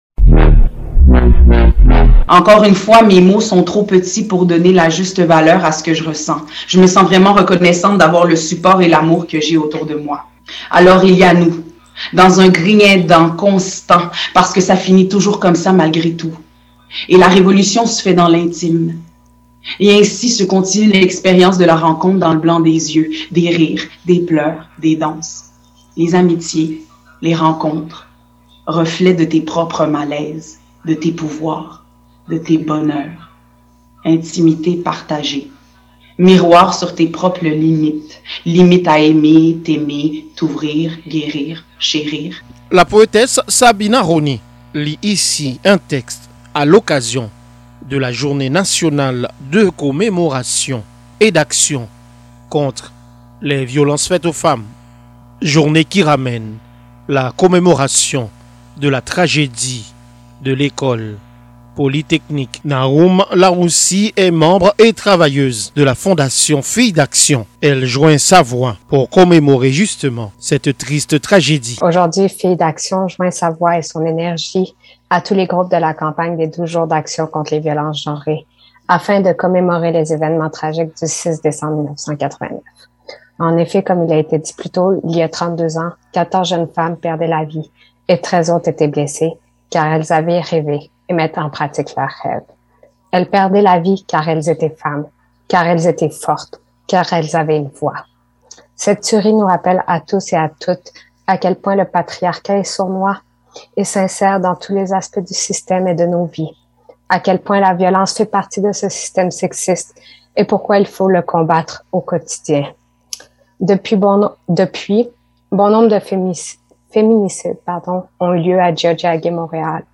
La rencontre a eu lieu lundi 6 décembre par zoom-conférence ou un regroupement d’organismes féminins ont joint leurs voix pour commémorer la tragédie de l’école polytechnique qui a fait 14 femmes victimes et clôturer les 12 jours d’actions contre les violences faites aux femmes débutées le 25 novembre dernier.
Déconfiner la parole et mettre fin aux violences quotidiennes était les thèmes de cette après-midi de commémoration tenue sur fond de discours et de prestations artistiques.